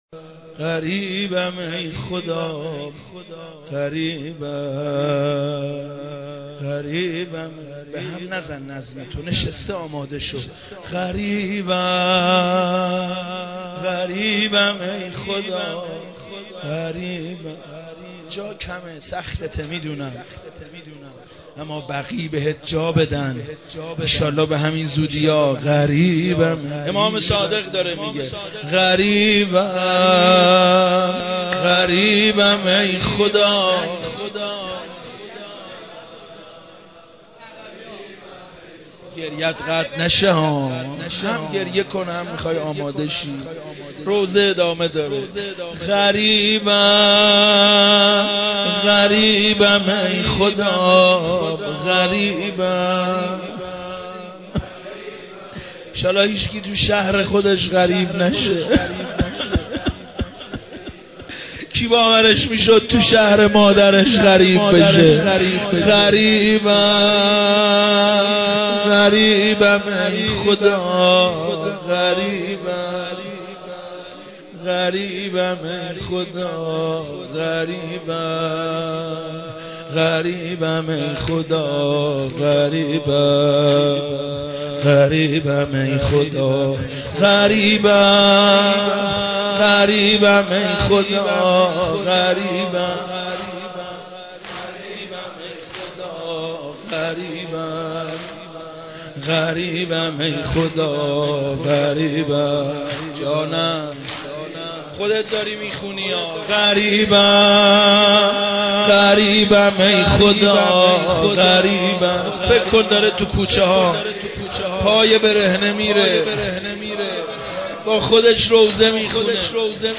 روضه و نوحه خوانی